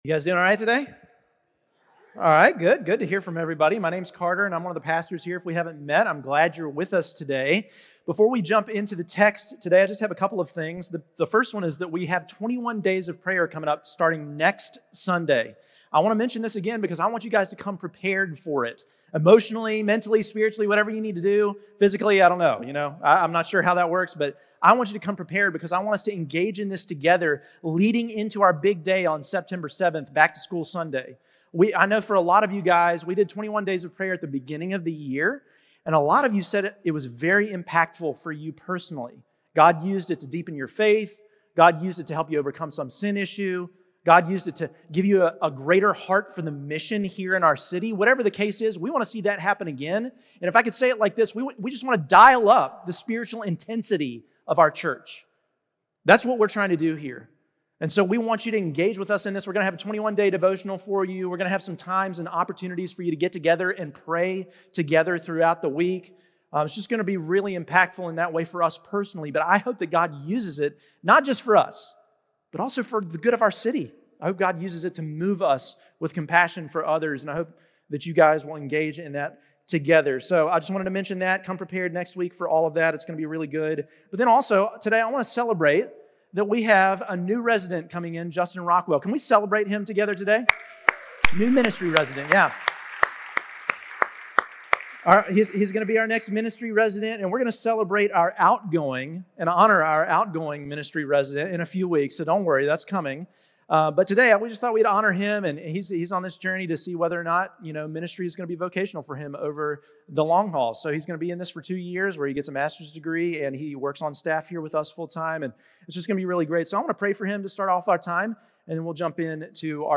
Redemption Church Sermons Forgiveness Isn't Free Aug 10 2025 | 00:47:17 1x 00:00 / 00:47:17 Subscribe Share Apple Podcasts Spotify Overcast RSS Feed Share Link Embed